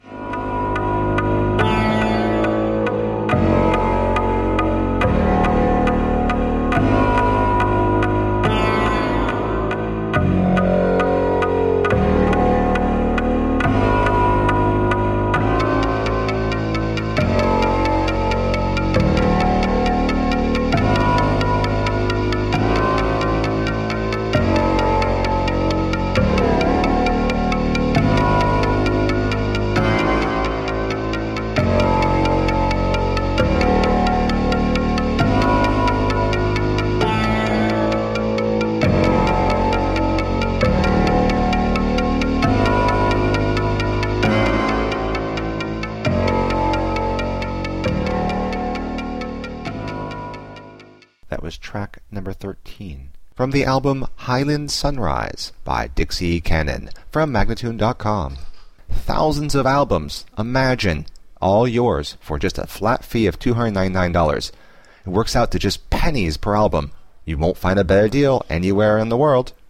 Tagged as: Alt Rock, Rock, Classic rock, Instrumental